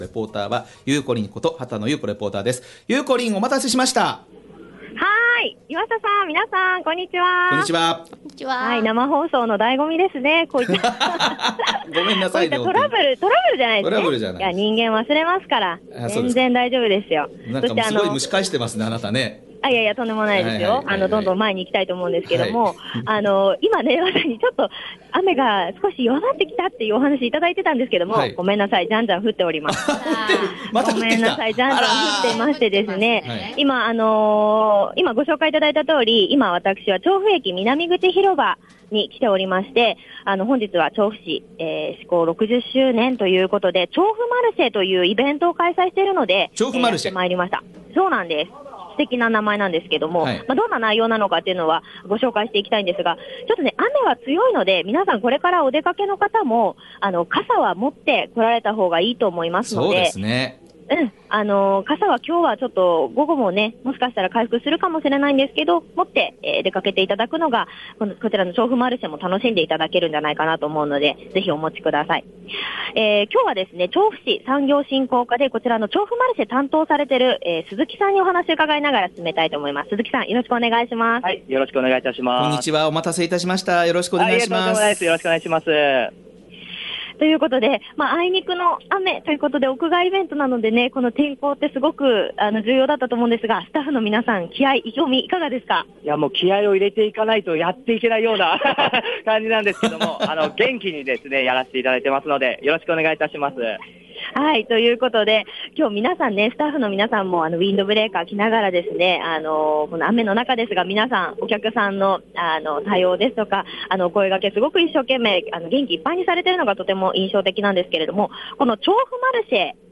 調布市制施行60周年を記念し、調布駅南口広場で2日間開催中『調布マルシェ』にお邪魔してきました☆
…が、あいにくの雨・雨・雨…